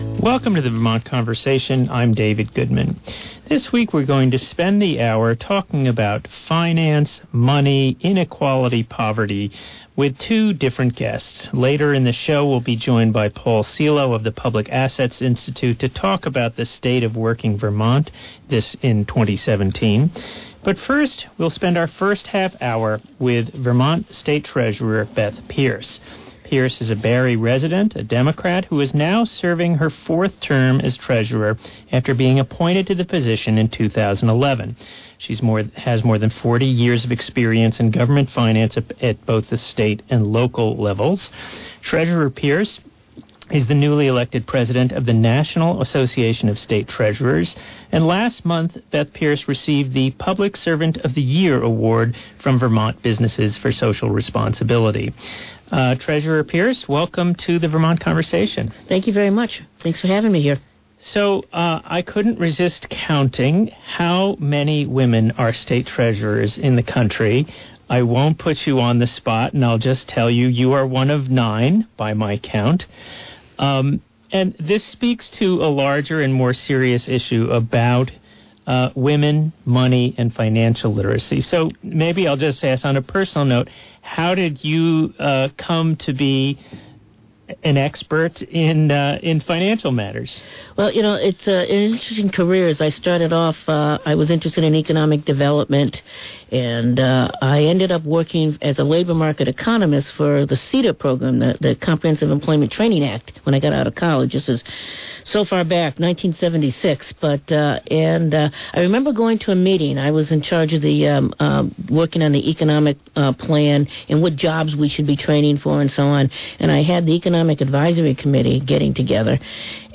(January 10, 2018 broadcast)
Beth Pearce, Vermont State Treasurer